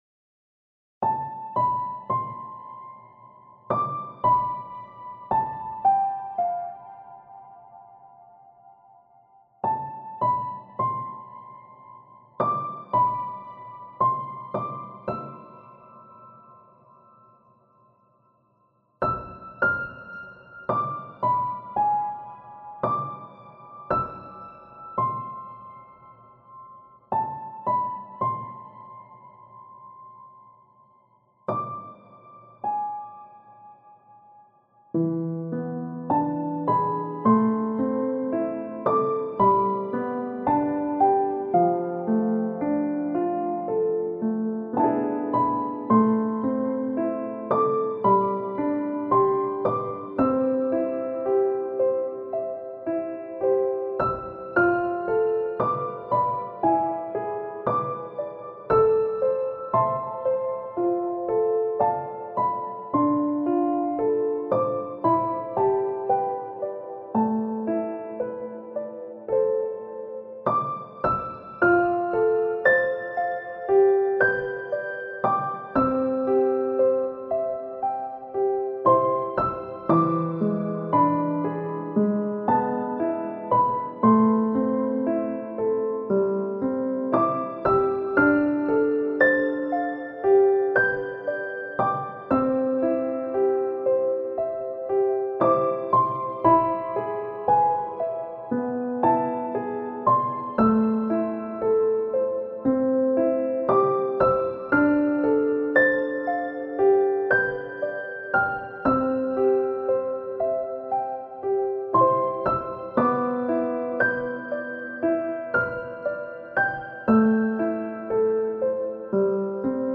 BGM
スローテンポロング暗い